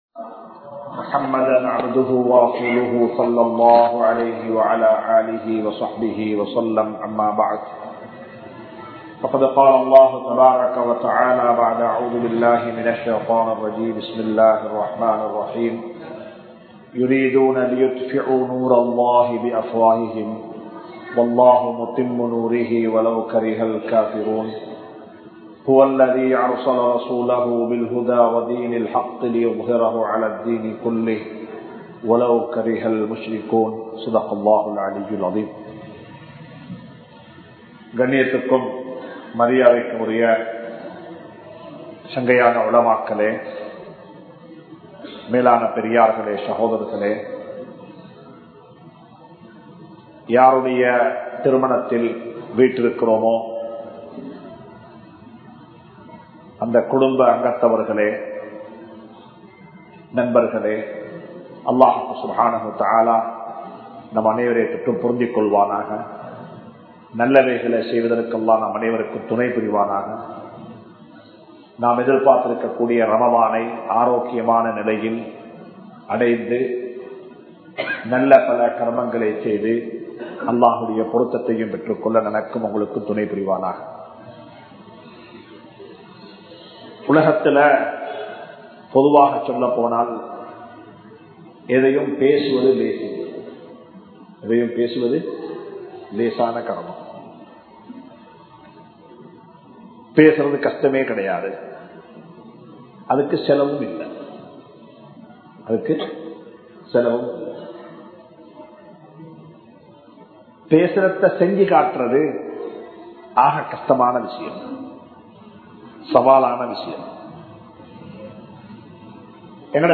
Islam Koorum Thirumana Vaalkai (இஸ்லாம் கூறும் திருமண வாழ்க்கை) | Audio Bayans | All Ceylon Muslim Youth Community | Addalaichenai
Yasir Arafath Jumua Masjidh